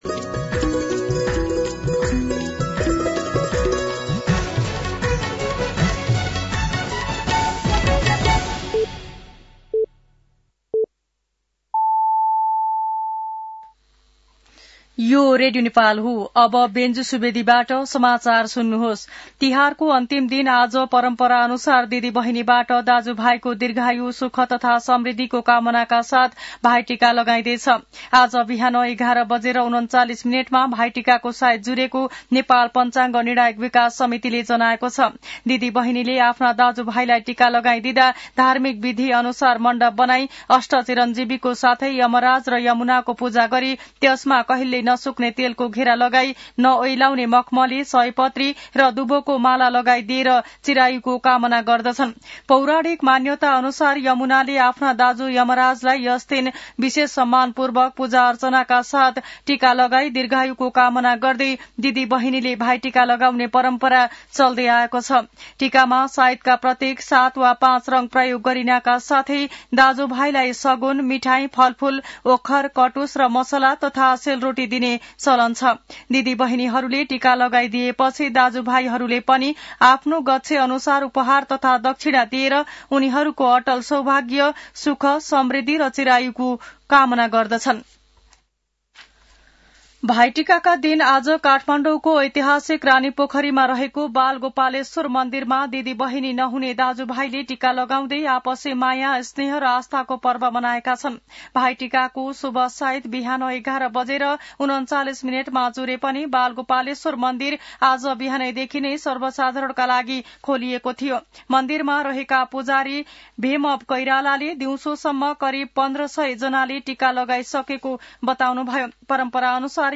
साँझ ५ बजेको नेपाली समाचार : ६ कार्तिक , २०८२